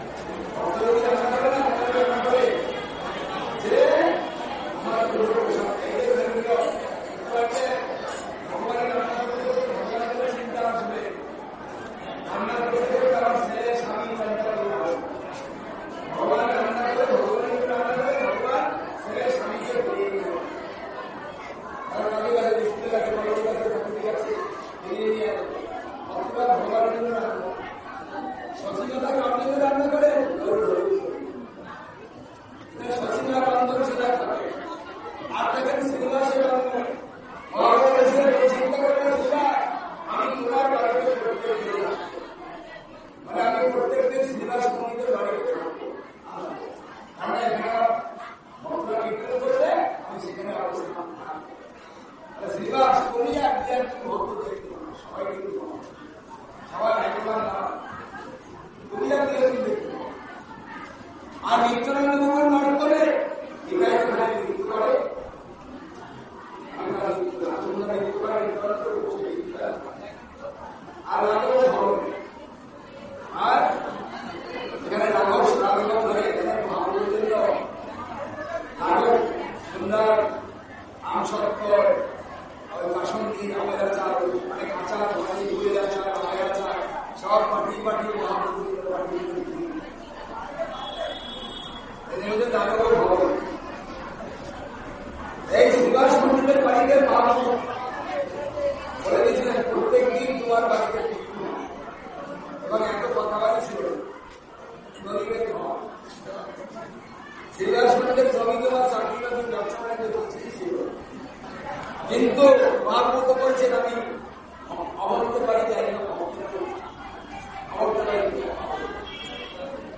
• Download / listen to the full class (6.9 Mb, 15 min | Bengali)